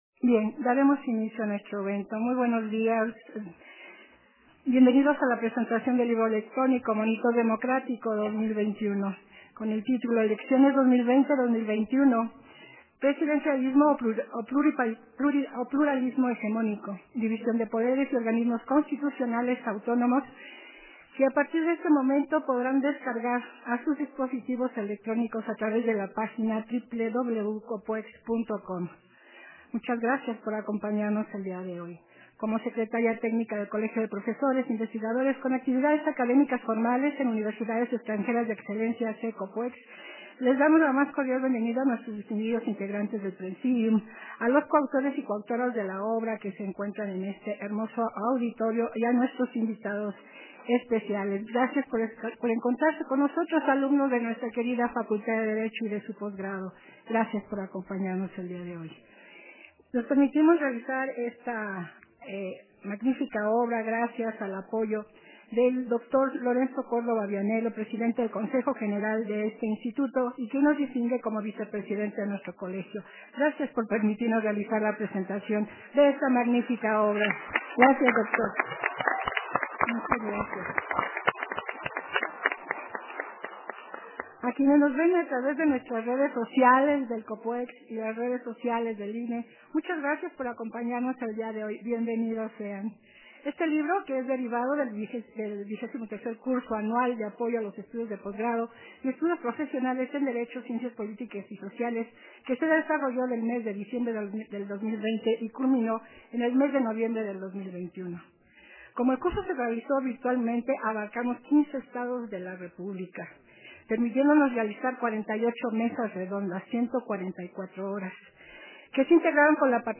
Versión estenográfica de la presentación del libro monitor democrático 2021